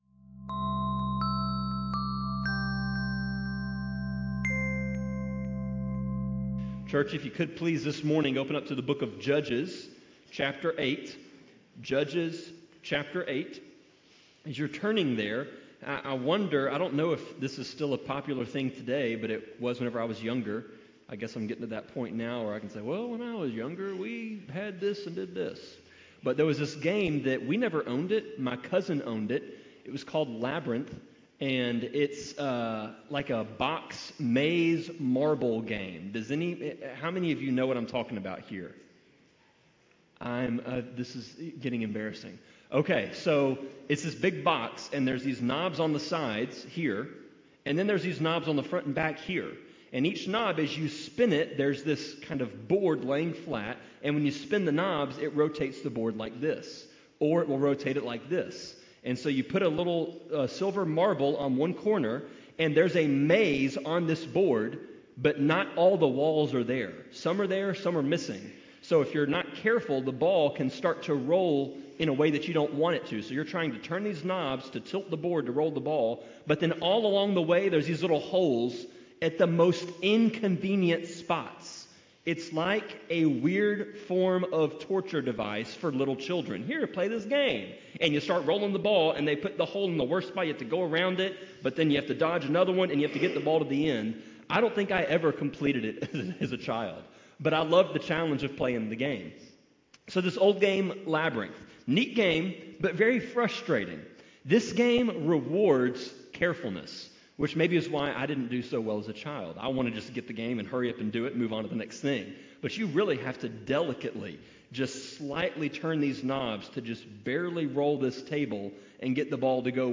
Sermon-25.11.9-CD.mp3